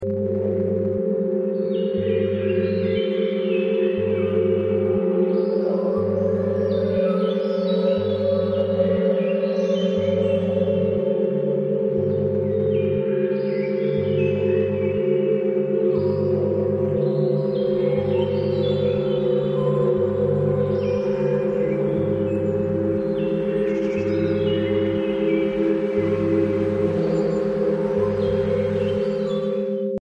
Heal Anxiety Fast | 396 Hz + 528 Hz + Nature Sounds (30s Frequency Reset)
Take 30 seconds to reset your nervous system with this powerful sound healing stack. This calming meditation blends: 🌿 396 Hz – Releases fear and emotional tension 💖 528 Hz – Promotes cellular healing and inner peace 🧠 8 Hz Binaural Beat – Induces relaxation and mental clarity 🌊 0.5 Hz Isochronic Pulse – Deep parasympathetic reset 🍃 Forest Nature Soundscape – Grounds you in peaceful Earth energy 🎧 Headphones recommended for full effect.